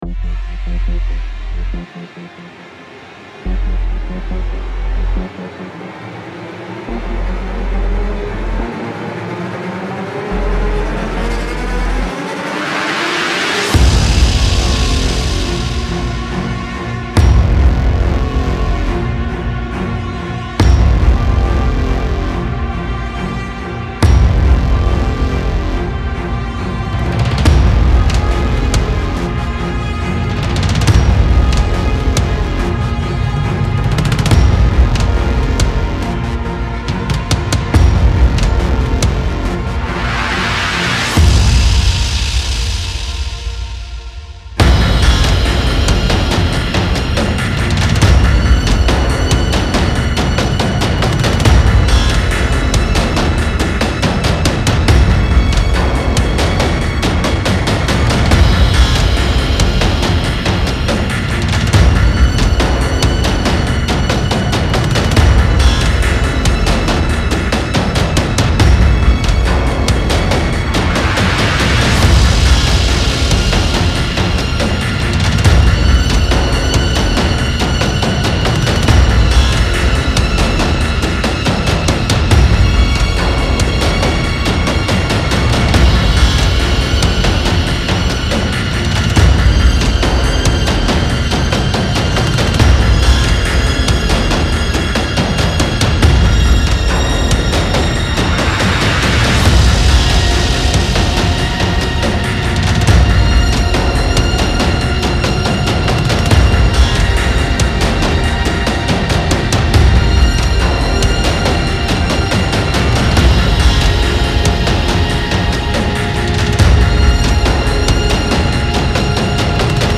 Style Style OrchestralSoundtrack
Mood Mood AggressiveDarkEpicIntenseScarySuspenseful
BPM BPM 70